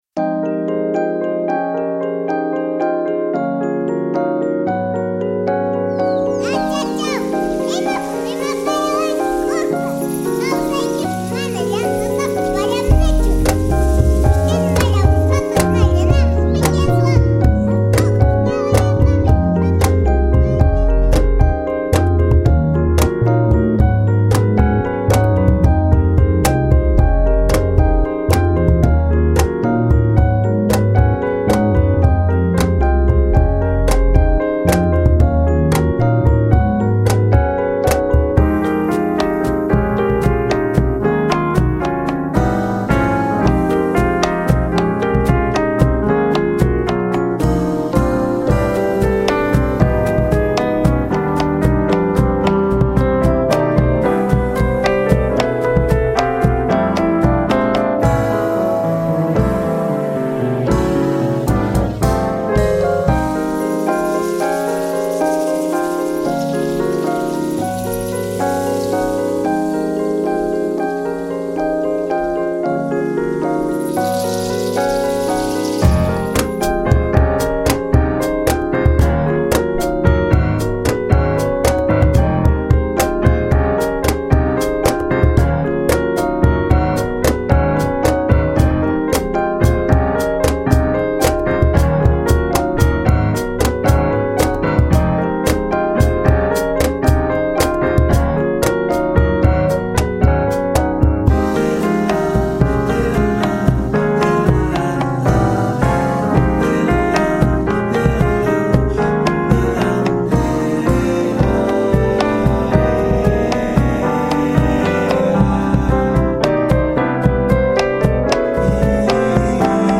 Categoría jazz fusión